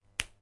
描述：我创建的源录音包的一部分，作为iOS/Android游戏 "Hipster Zombies "声音设计的一部分
标签： 声音设计 胡萝卜 蔬菜 休息 有机 紧缩
声道立体声